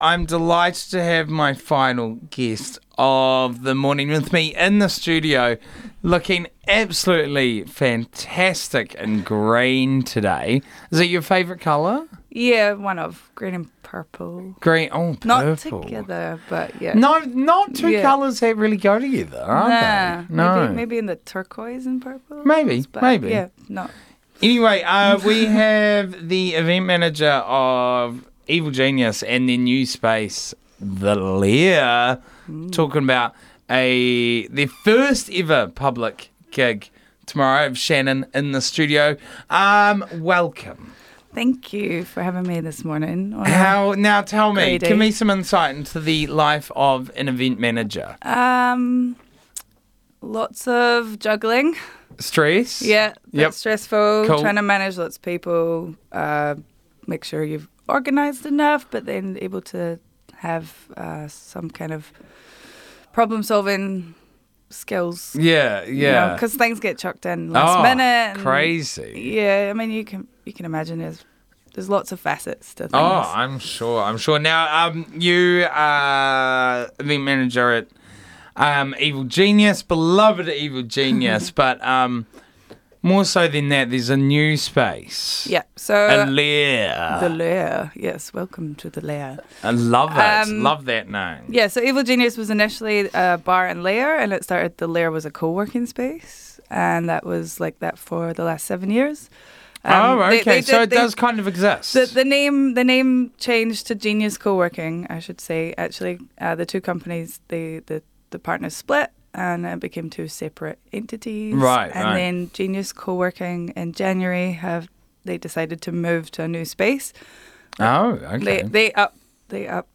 popped by the RDU studio to talk about their new space, The Lair.